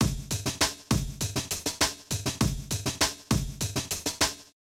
NoiseEdited Amen Break
Normal Loop